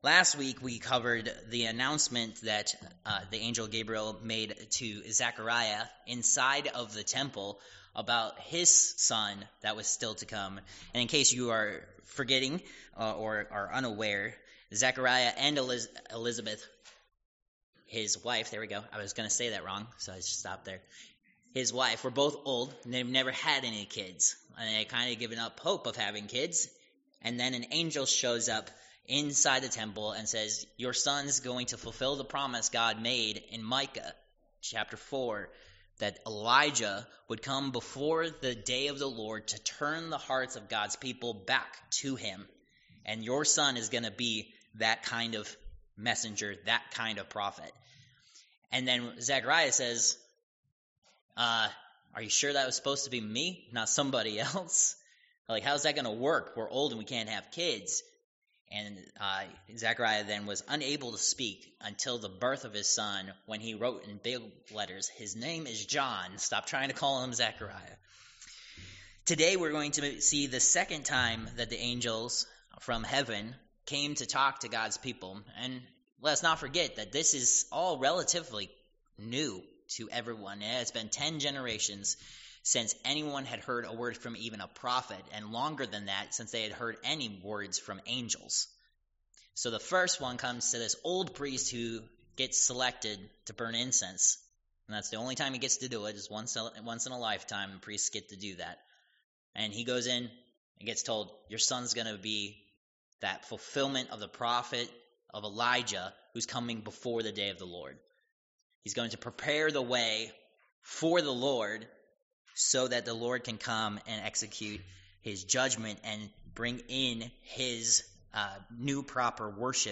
Passage: Luke 1:26-56 Service Type: Worship Service